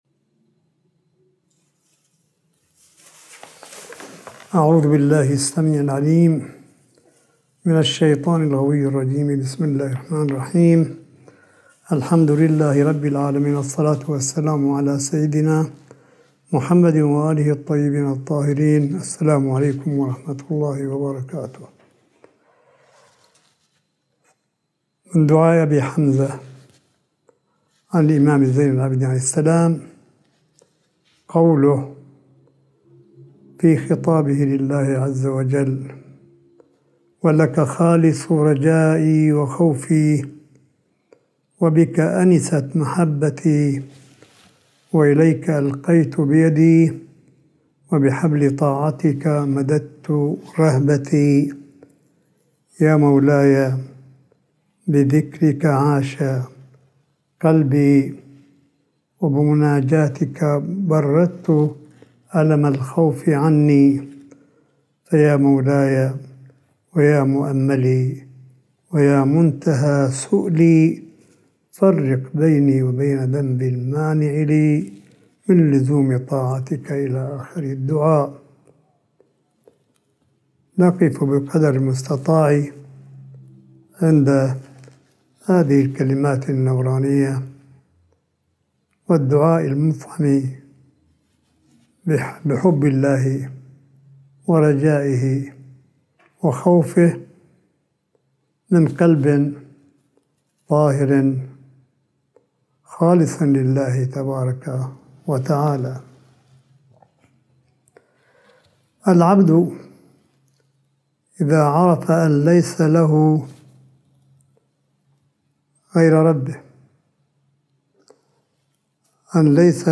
ملف صوتي للحديث الرمضاني (3) لسماحة آية الله الشيخ عيسى أحمد قاسم حفظه الله – 3 شهر رمضان 1442 هـ / 15 أبريل 2021م